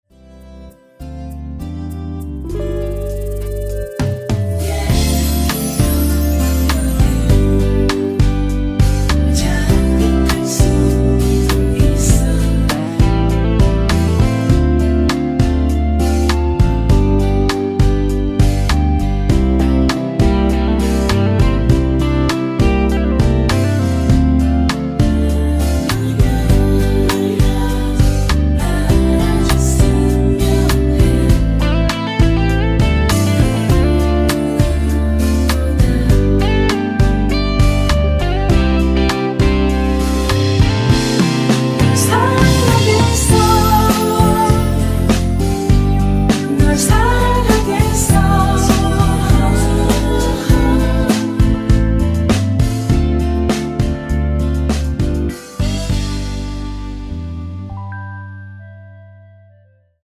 ◈ 곡명 옆 (-1)은 반음 내림, (+1)은 반음 올림 입니다.
앞부분30초, 뒷부분30초씩 편집해서 올려 드리고 있습니다.